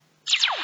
laser1.mp3